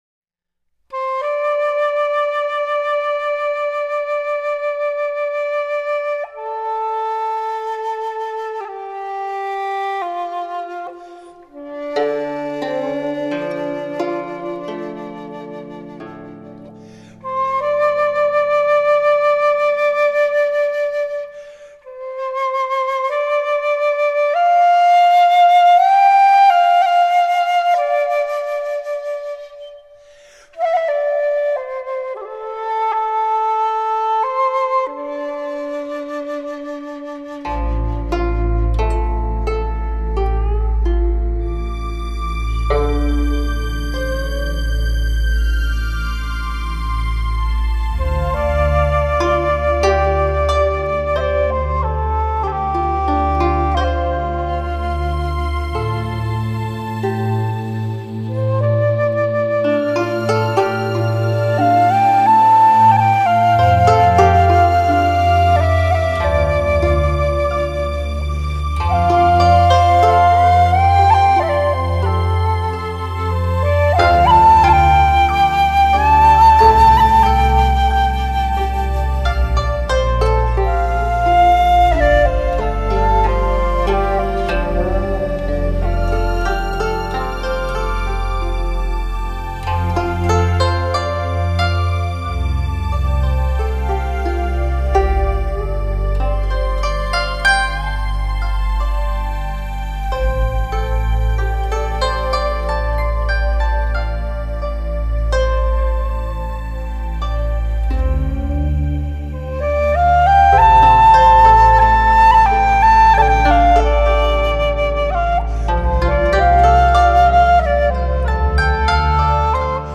乐风： 东方新世纪